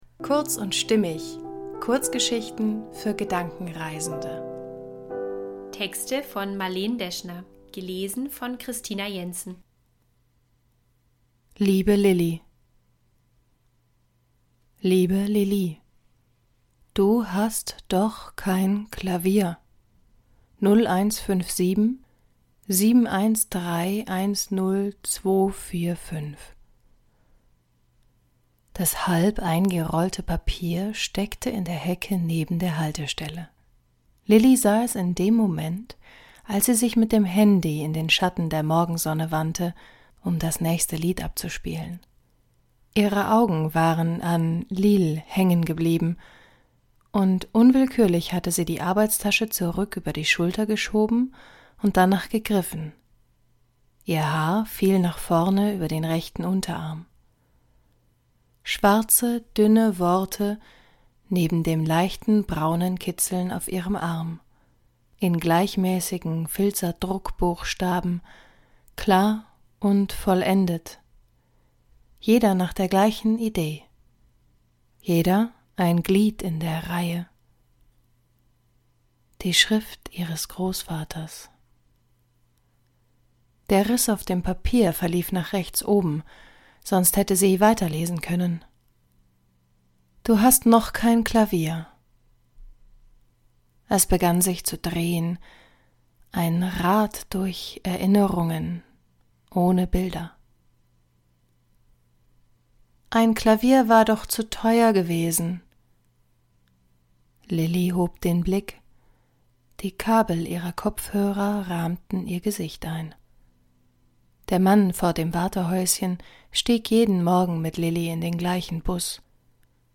kurz & stimmig - Kurzgeschichten für Gedankenreisende